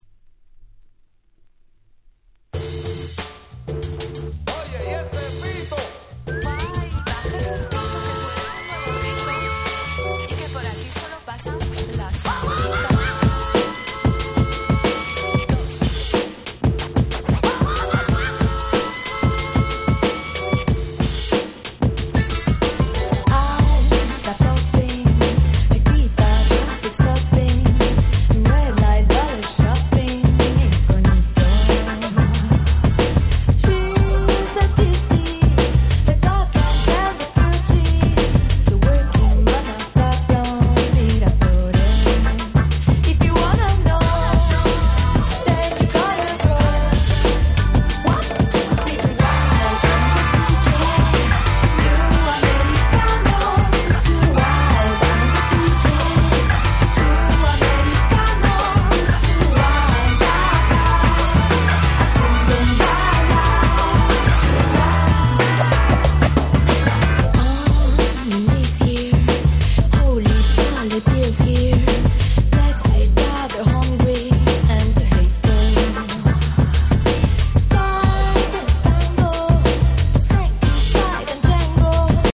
UK出身の美人女性シンガーでシングルは93年からリリースしておりました。
Crossoverな層に受けそうな非常にオシャレな楽曲ばかりです！
Bossa